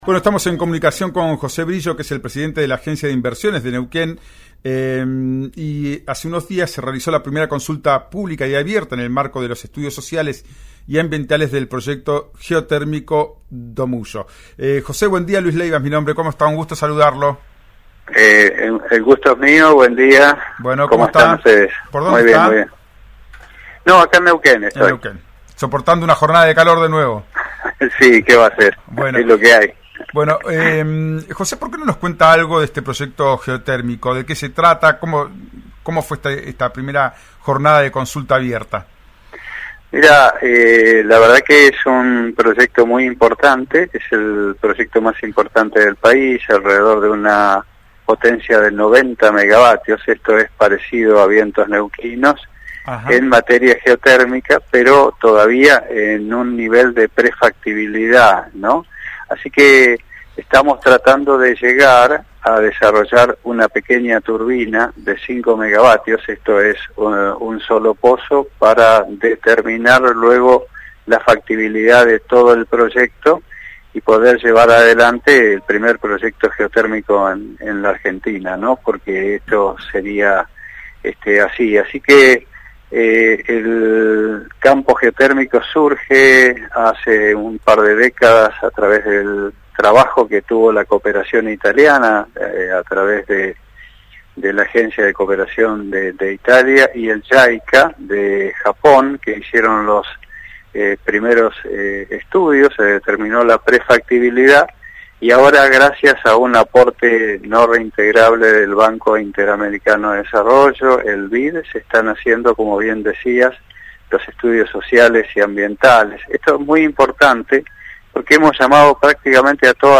Escuchá a José Brillo, presidente de la Agencia de Inversiones del Neuquén, en «Ya es tiempo» por RÍO NEGRO RADIO: